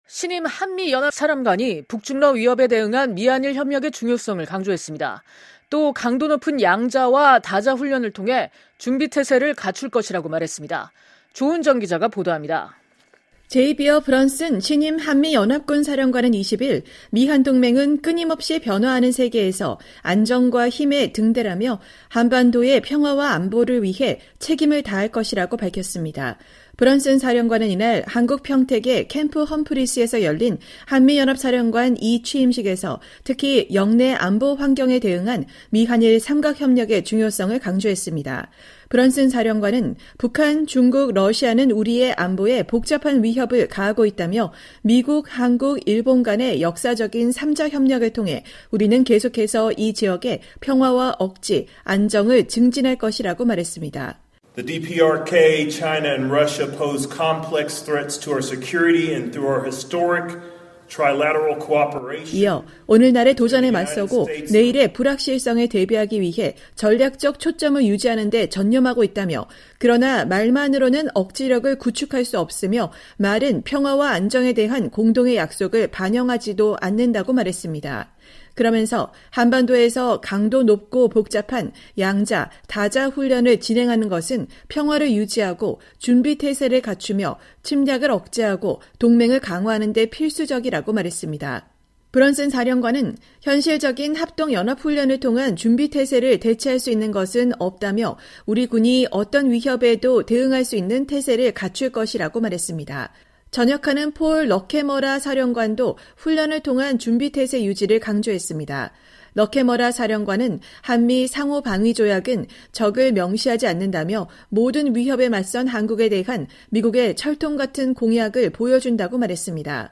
2024년 12월 20일 한국 평택 캠프 험프리스에서 열린 한미연합사령관 이취임식에서 제이비어 브런슨 신임 사령관이 연설하고 있다.